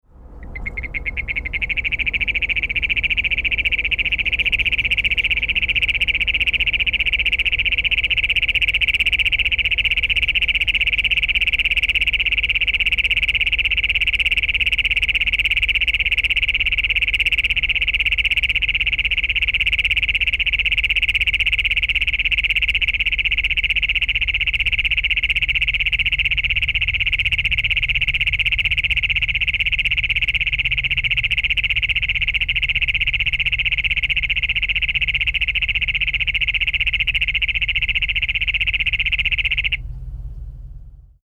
The call of the Great Plains Toad is an explosive jackhammer-like metallic trill lasting from 5 seconds to almost a minute.
This is a 41 second unedited recording of a nocturnal advertisement call of a male single toad calling from an agricultural drainage in Riverside County, California (shown to the right.)